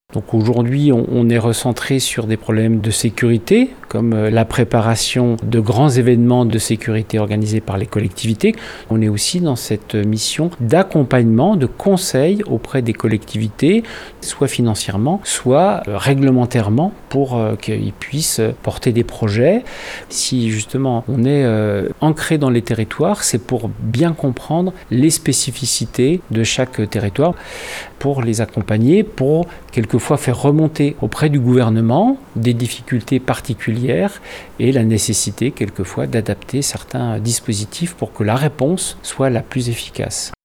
Alors, à quoi sert aujourd'hui un sous-préfet ? La réponse de Stéphane Donnot.